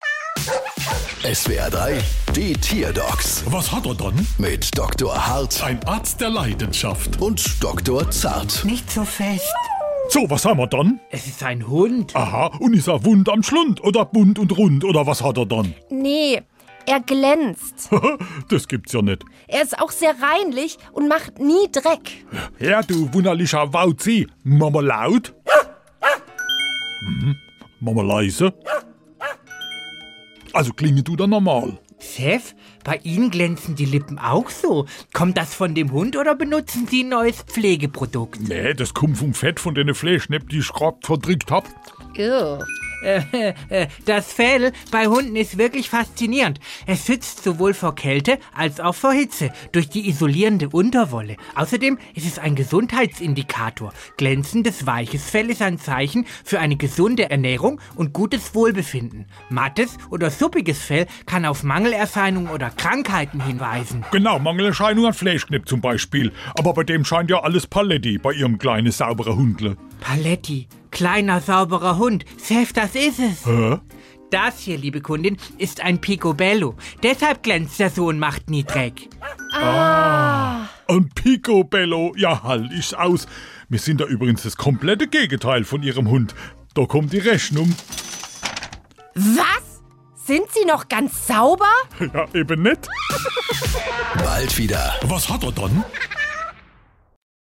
SWR3 Comedy Die Tierdocs: Hund glänzt